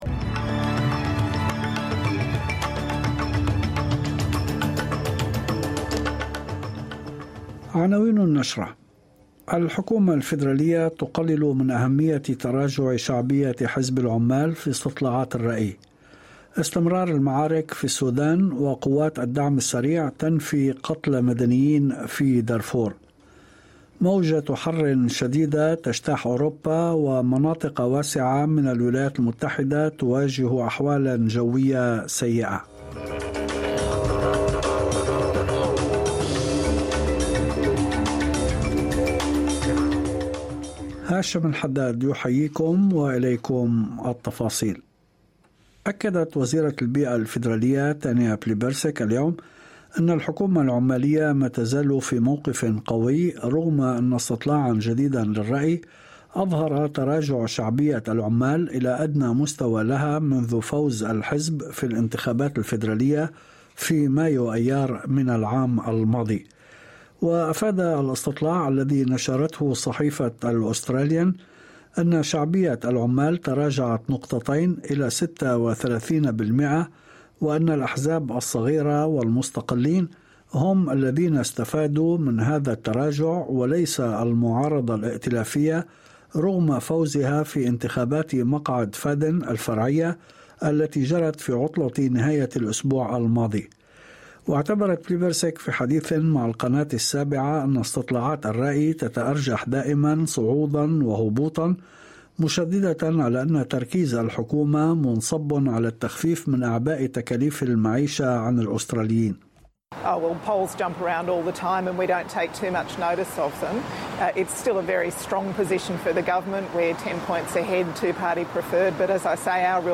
نشرة أخبار المساء 17/07/2023